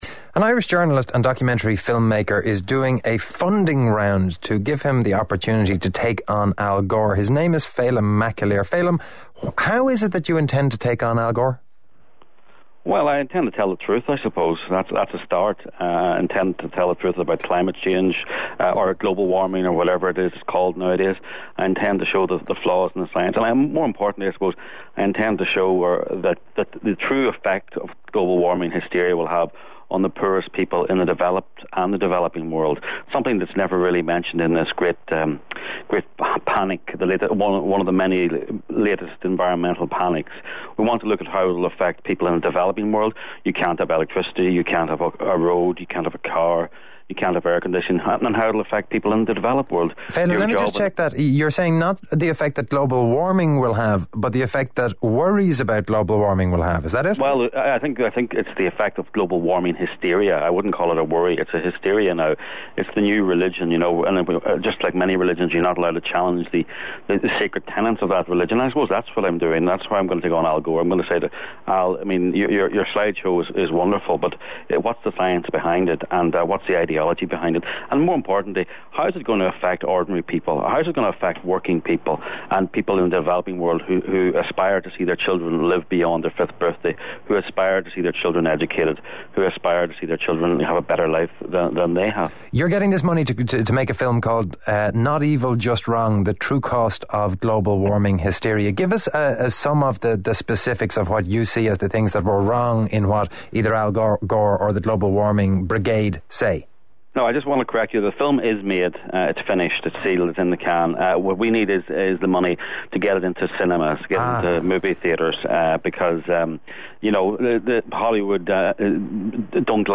Click here to listen to TodayFM interview
It went downhill from there, with nearly 17 minutes of bare-knuckle “debate”.